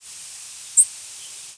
Vesper Sparrow diurnal flight calls